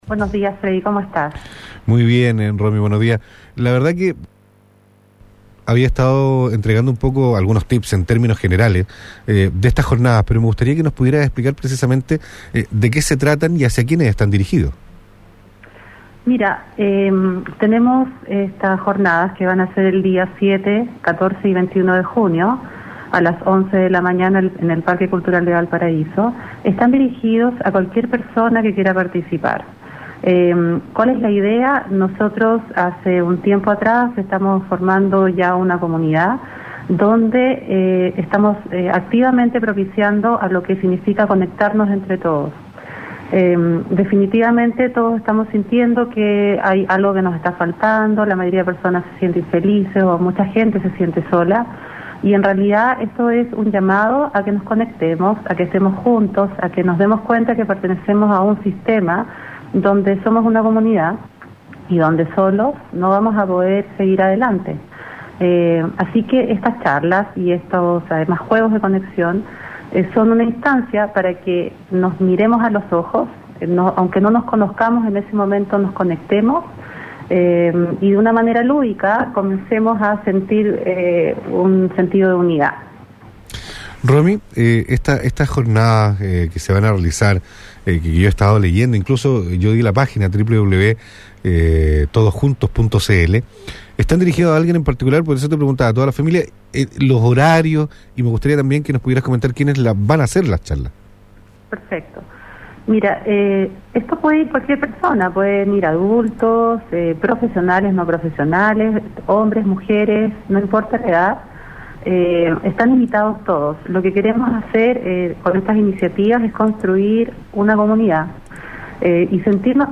Escucha acá la entrevista a una de las organizadores en radio UCV donde nos cuenta de que van a tratar las jornadas que vienen.